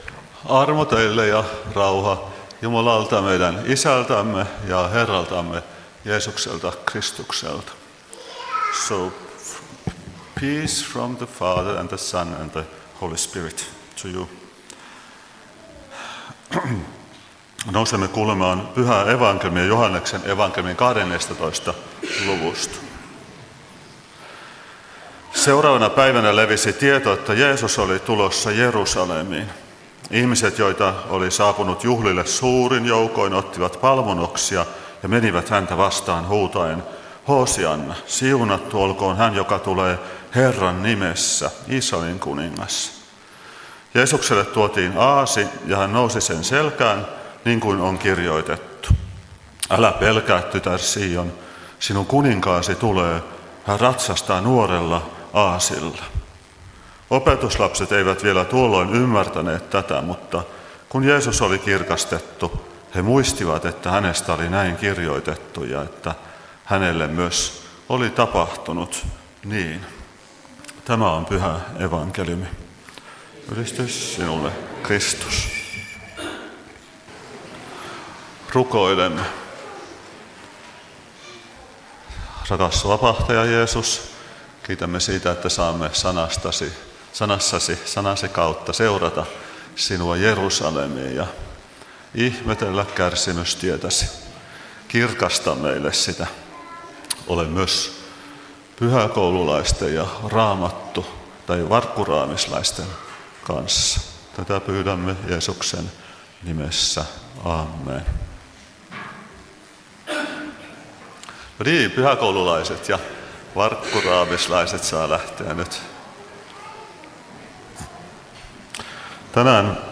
Kokoelmat: Tampereen Luther-talo